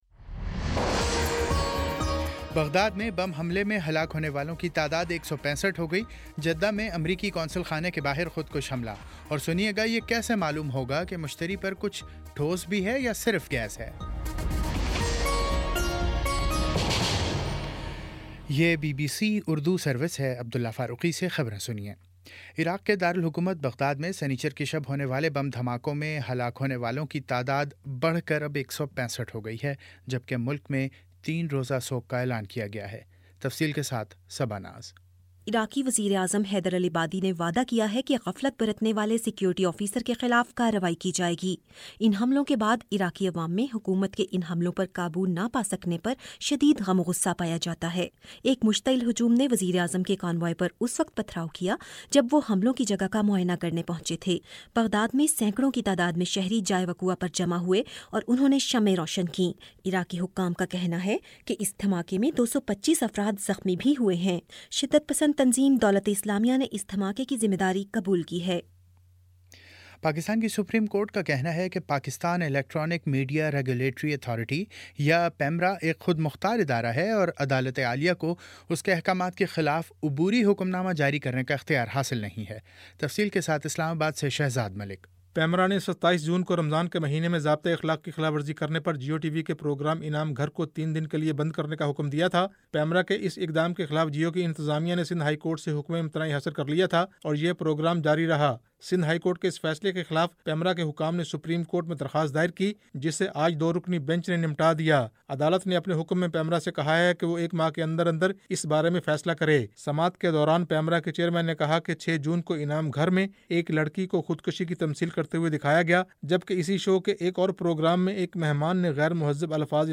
جولائی 04 : شام پانچ بجے کا نیوز بُلیٹن